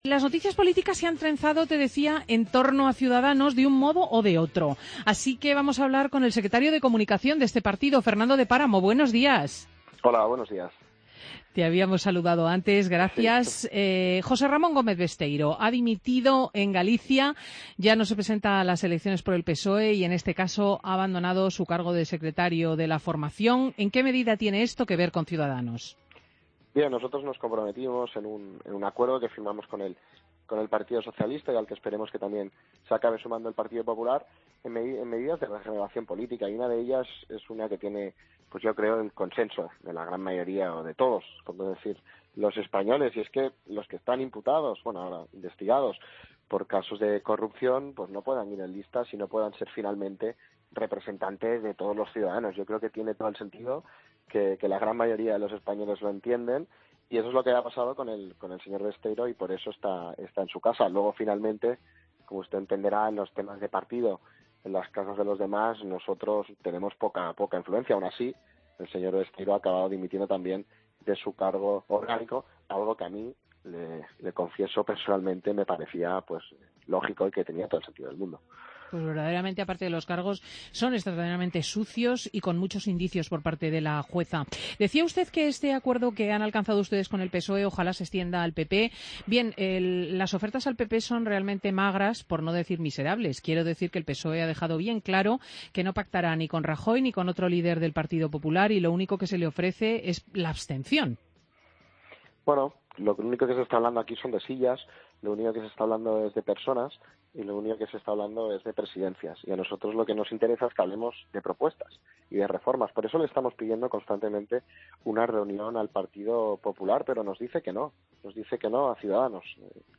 AUDIO: Escucha la entrevista a Fernando de Páramo, Secretario de Comunicación de Ciudadanos, en Fin de Semana COPE.